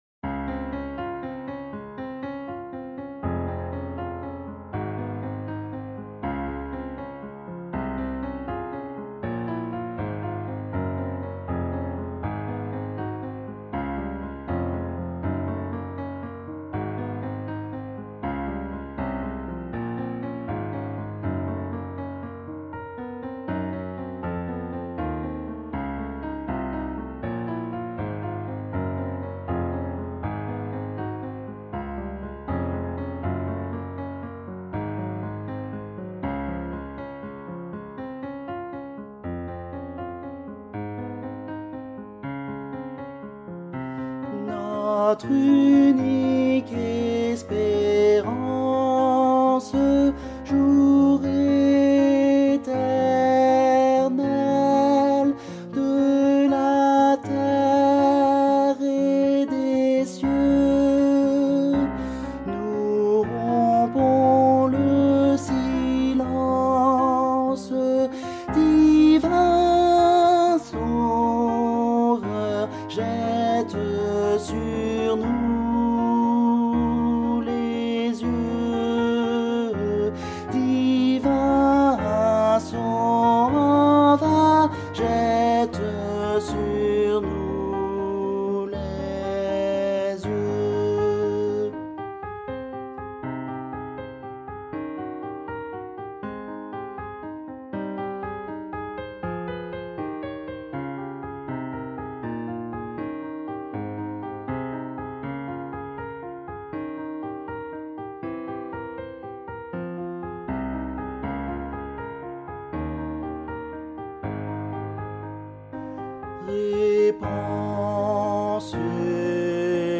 R�p�tition de la pi�ce musicale N
Cantique de Jean Racine - Ténor.mp3